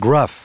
Prononciation du mot gruff en anglais (fichier audio)
Prononciation du mot : gruff